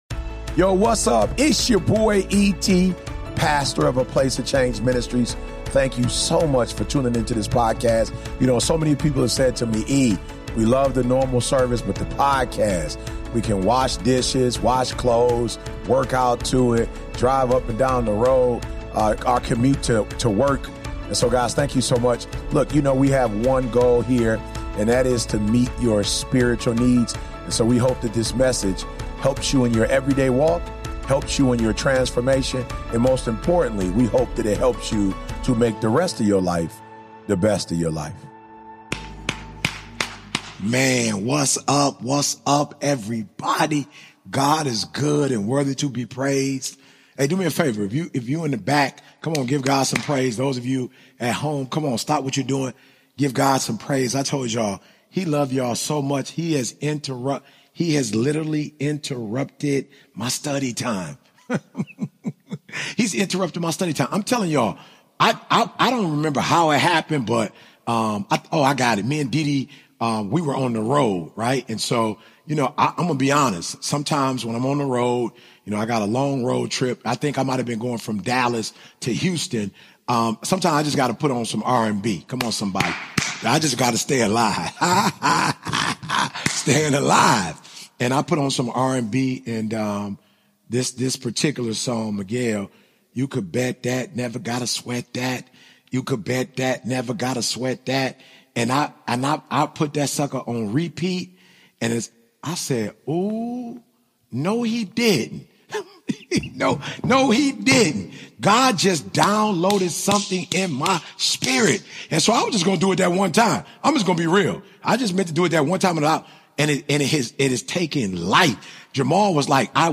Christianity, Religion & Spirituality, Spirituality
In this raw and unfiltered message, Dr. Eric Thomas exposes the #1 mistake we make when we don't see instant results: we interfere.